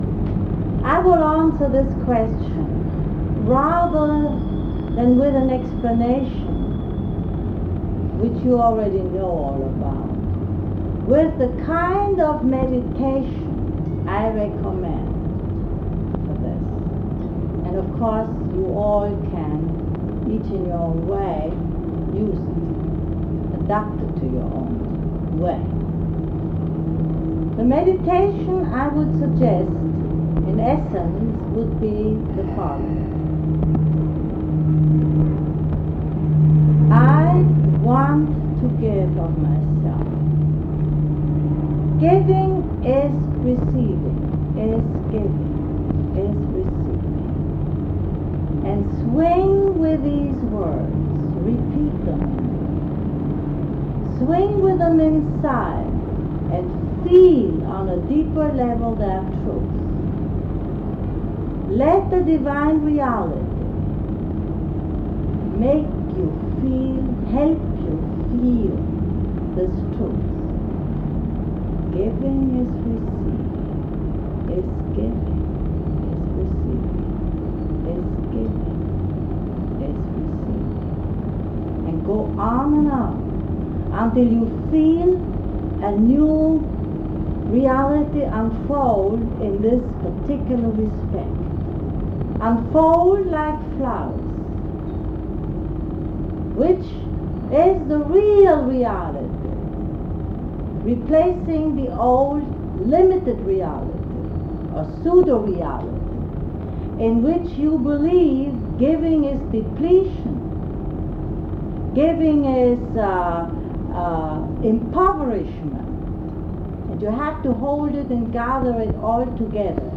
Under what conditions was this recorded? Special: 6 mins from the original 1960 tape recording: